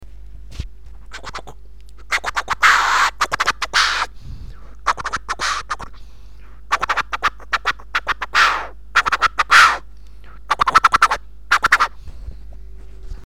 Форум российского битбокс портала » Реорганизация форума - РЕСТАВРАЦИЯ » Выкладываем видео / аудио с битбоксом » Мои Тупые Биты.
Это Скретч. Только Скретч. мне нужна критика не по битам а знанию звуков.